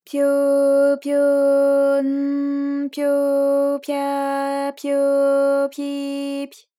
ALYS-DB-001-JPN - First Japanese UTAU vocal library of ALYS.
pyo_pyo_n_pyo_pya_pyo_pyi_py.wav